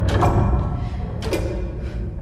clock.mp3